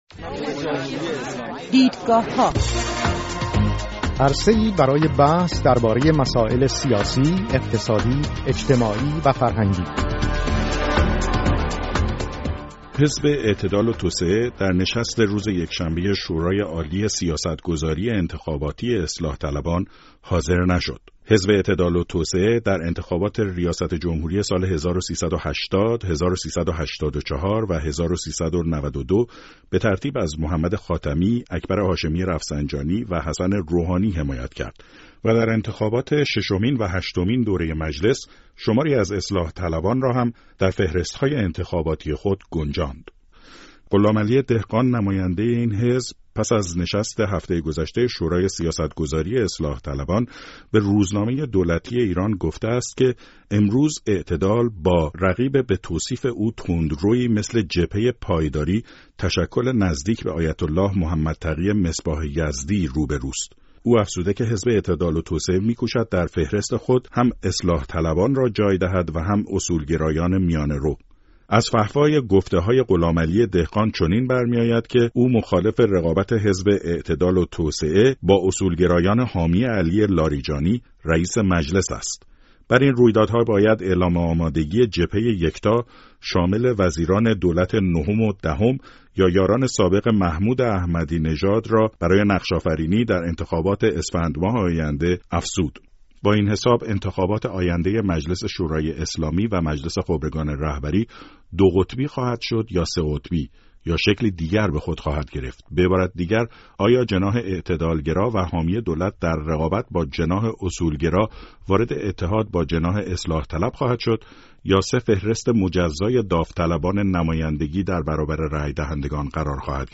چهار میهمان این هفته برنامه «دیدگاهها» از جمله به این پرسش ها پاسخ داده اند.